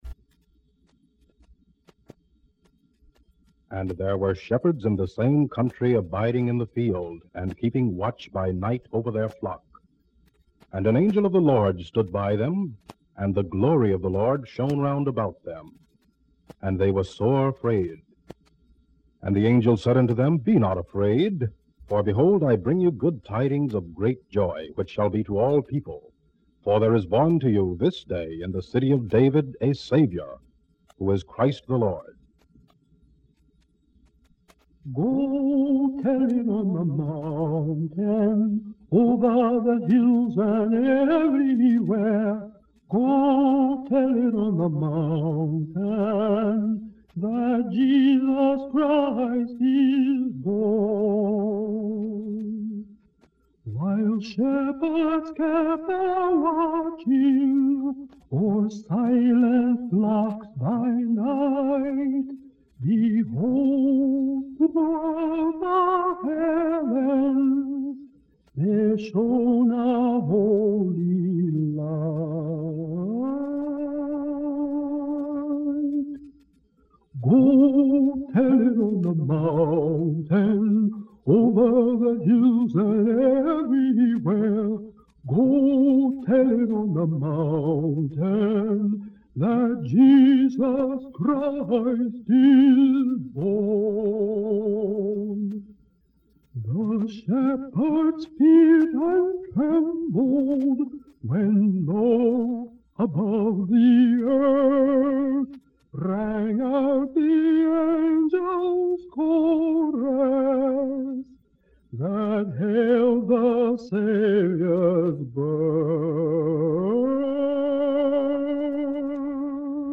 rustin sings spirituals.mp3